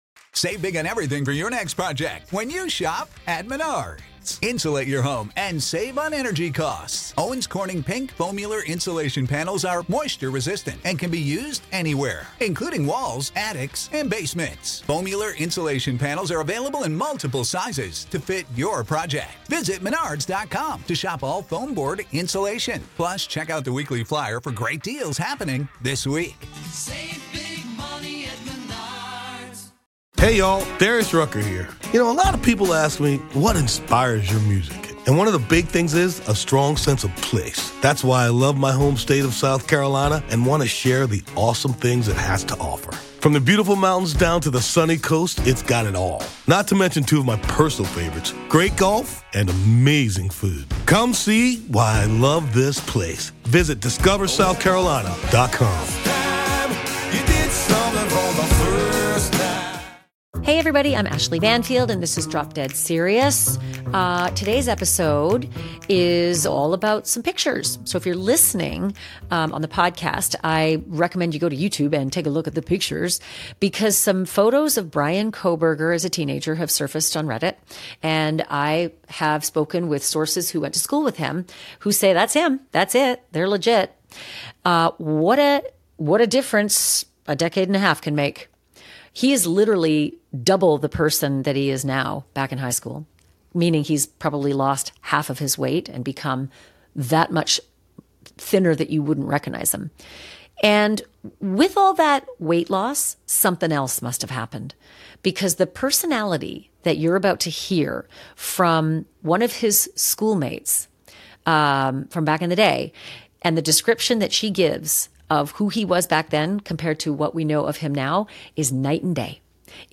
In this episode, Ashleigh Banfield speaks with a classmate of Kohberger’s sister, who remembers the family and the shockwaves that tore through their community.